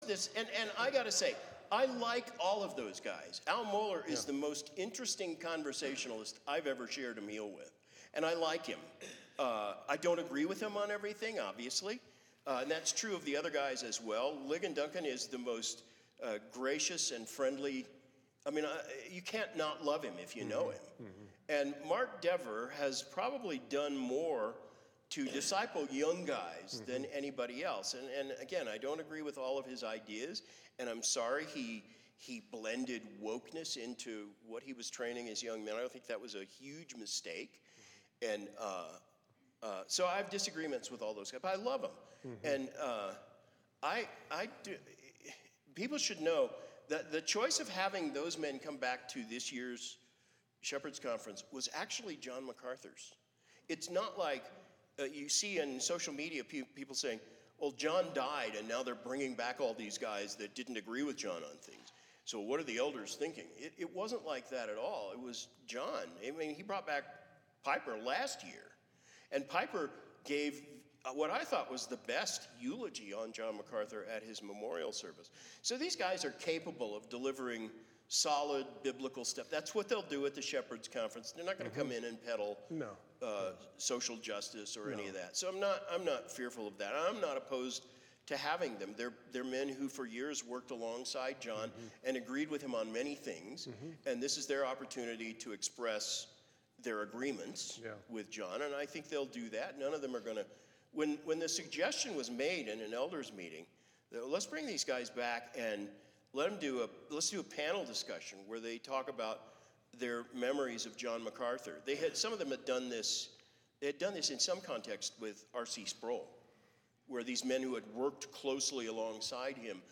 shared at The Master’s Fellowship regional meeting in Denver: